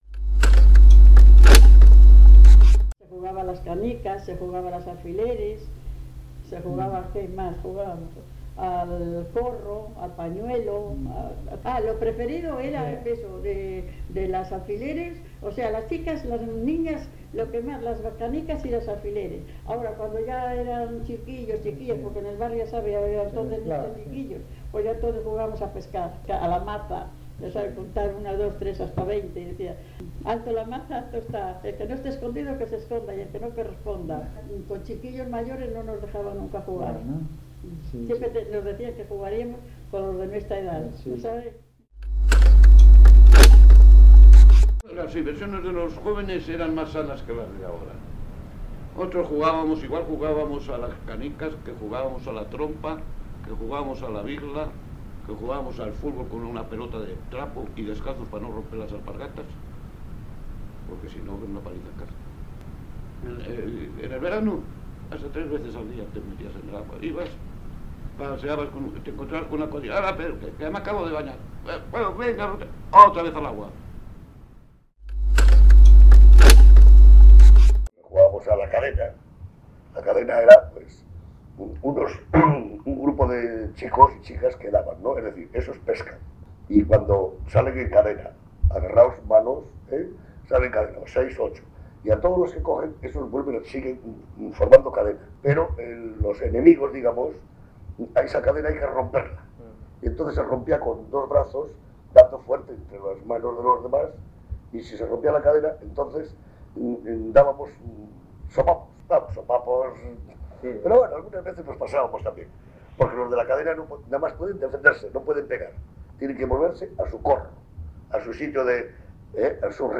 Historia oral del valle de Camargo